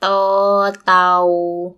– dtoor _ dtau